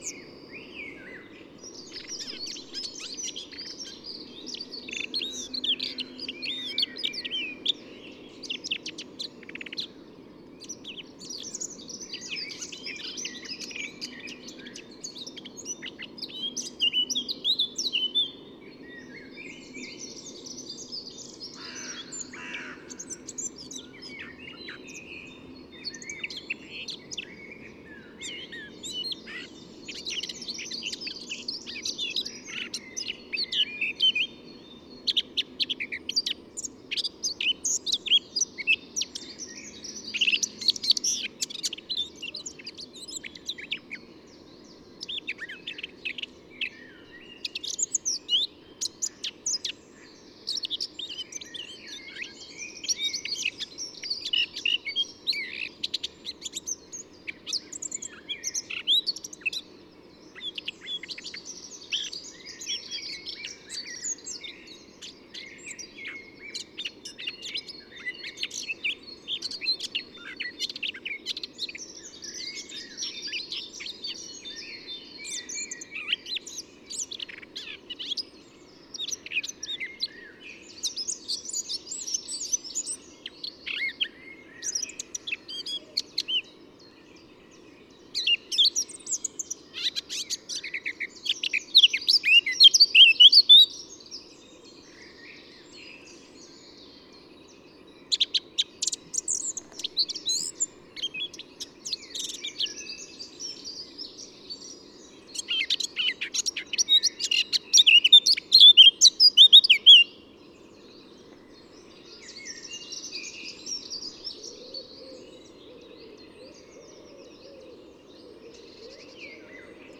In the following recording you can hear the more quiet plastic song is less
dynamic (break-outs) and more cherent verses in comparison to the crystallised
100402, Blackcap Sylvia atricapilla, plastic song, Leipzig, Germany
10_blackcap.mp3